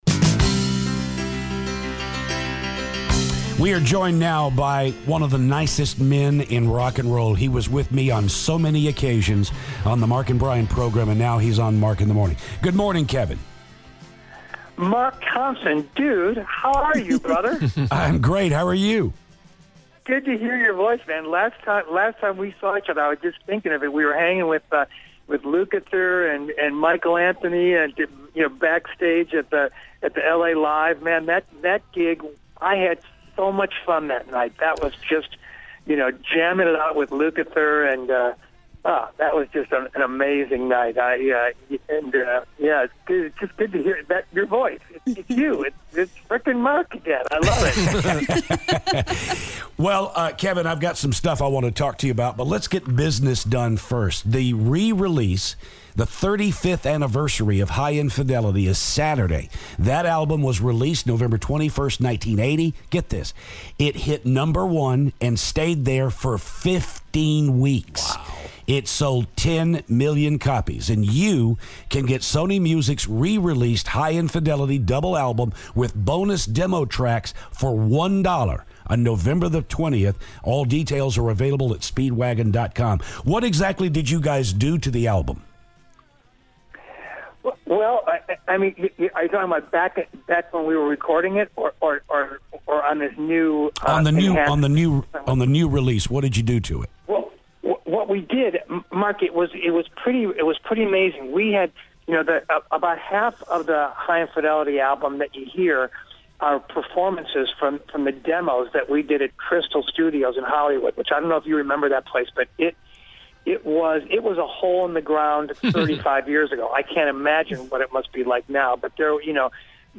REO Speedwagon's Kevin Cronin calls the show!